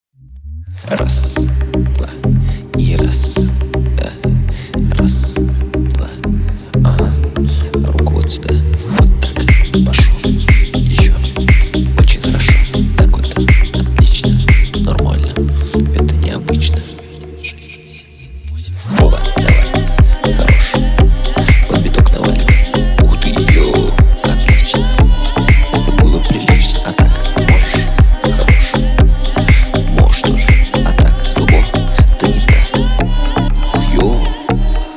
Жанр: Танцевальные / Русские